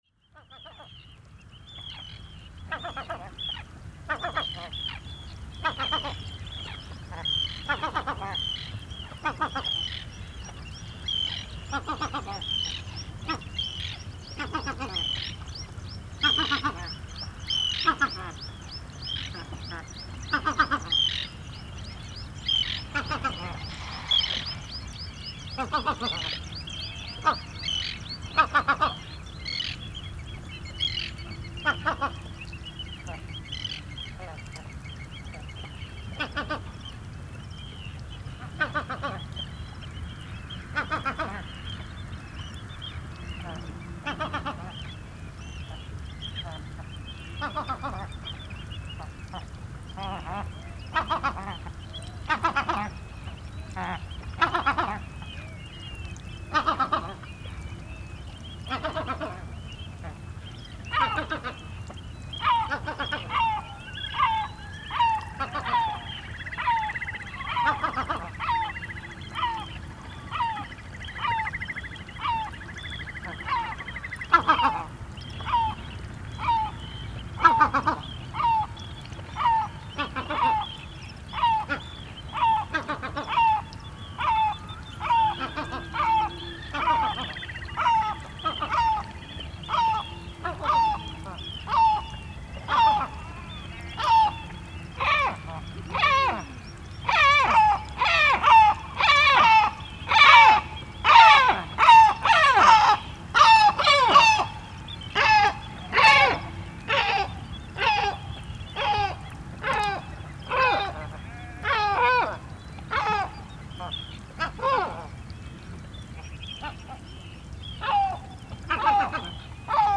Just a minute before the recording started I had to move the microphones to a slightly more sheltered spot, because over the night the wind started to increase which unfortunately can still be heard in the recording. The recording is „unprocessed“. Recorded at +50dB gain, normalized up to -7dB by adding +20dB in post, fade in and out and then converted to mp3. The recording starts exactly at 4:00 in the morning on June 22, 2023 and ends just over half an hour later. After a high tide earlier in the night, the tide was already starting to recede.